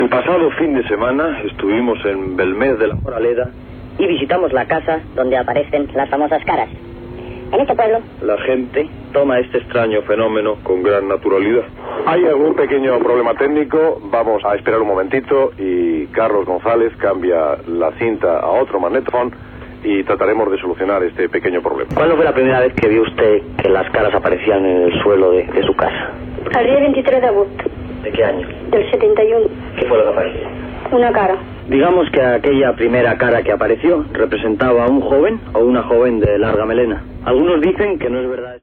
Resposta d'una de les testimonis.
Divulgació